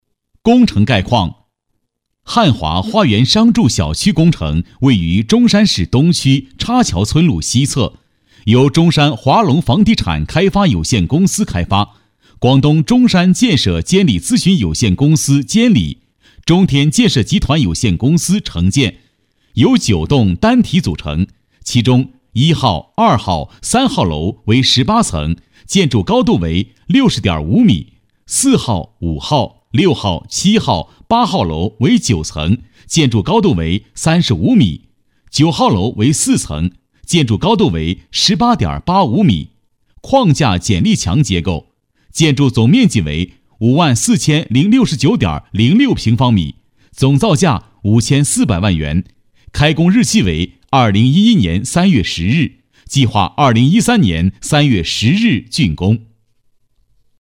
男国162_多媒体_项目规划_汉华花园商住小区工程.mp3